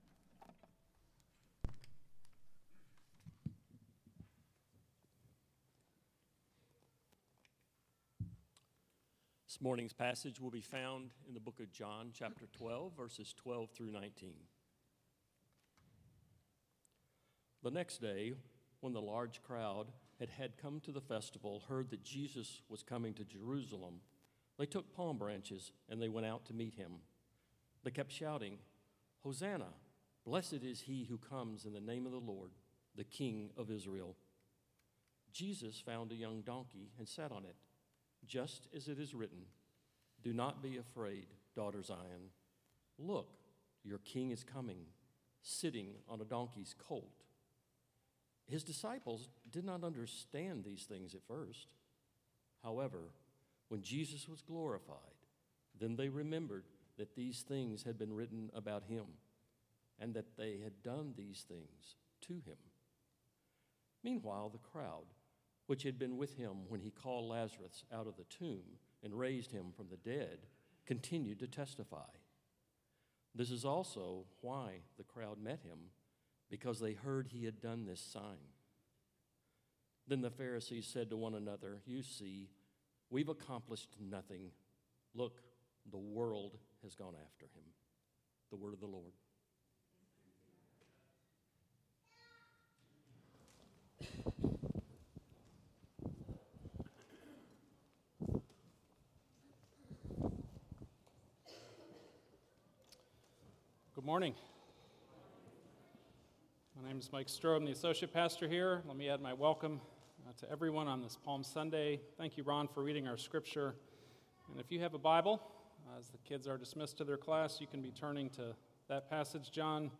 Hear expository sermons from the teaching team of Trinity Fellowship Church in Richardson, Texas.